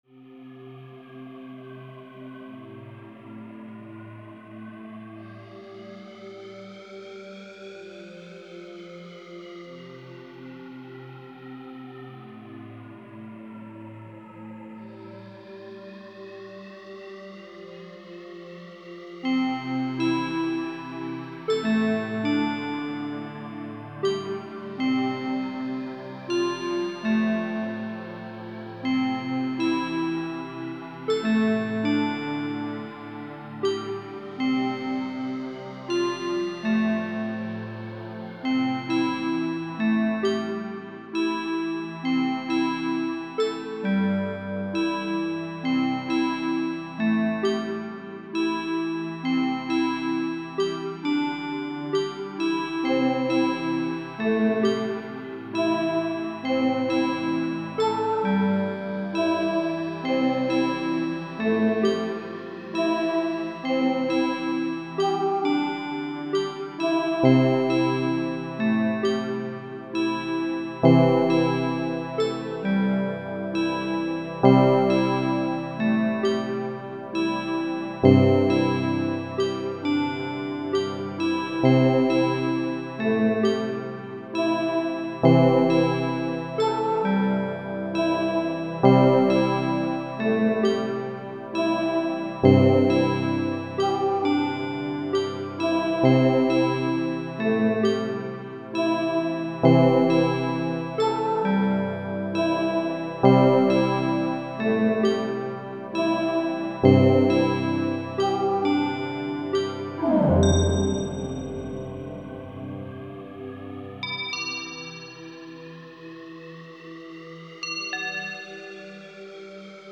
3: I've written a title theme for the game!!!